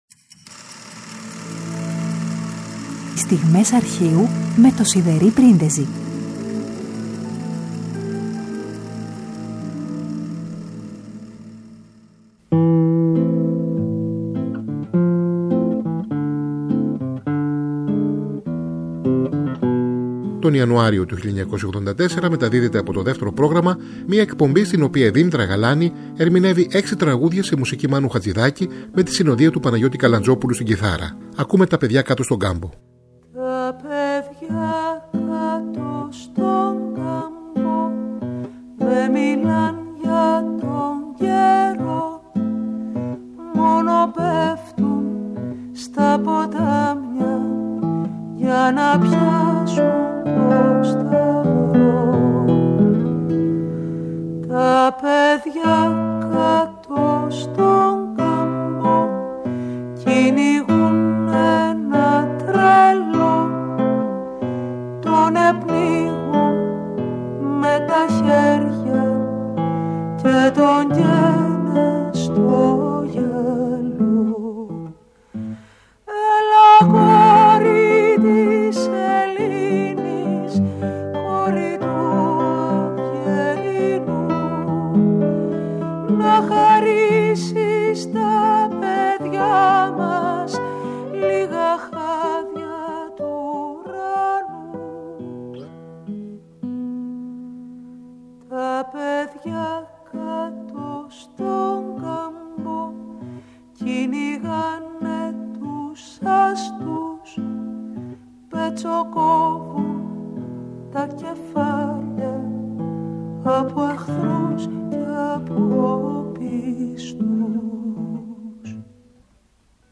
κιθάρα